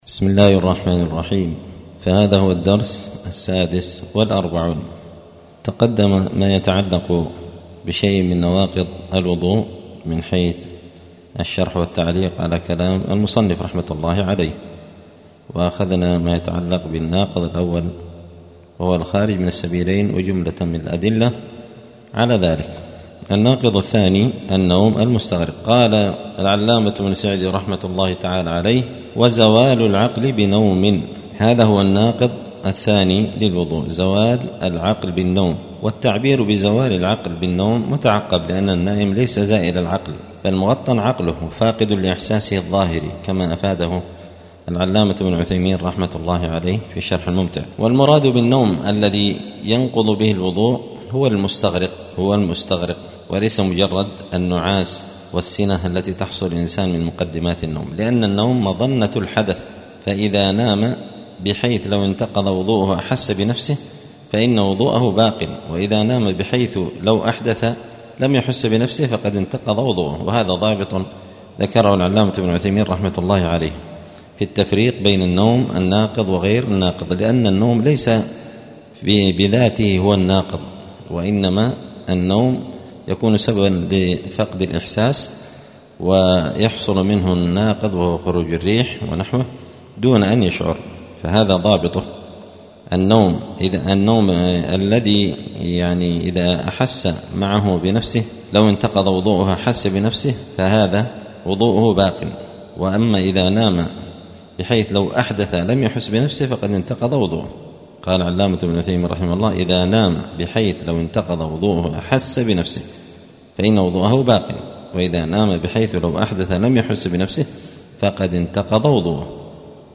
*الدرس السادس والأربعون (46) {كتاب الطهارة باب نواقض الوضوء النوم المستغرق}*